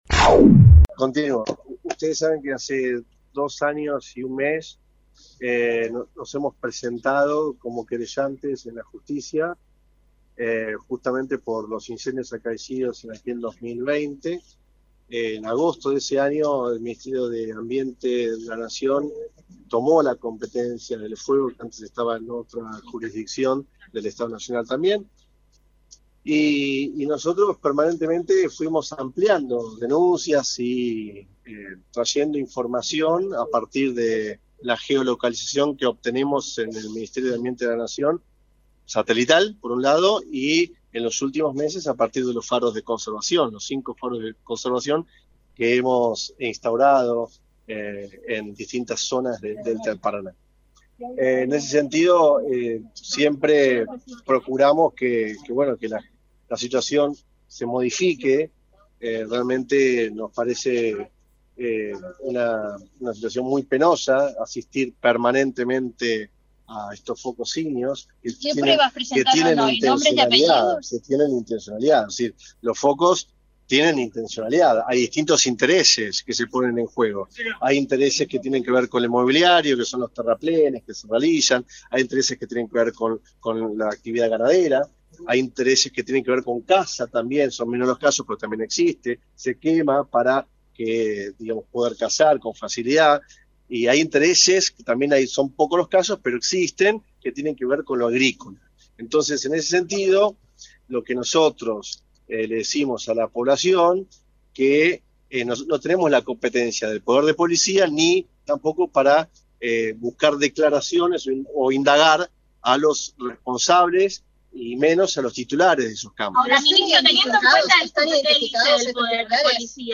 cabandie-conferencia.mp3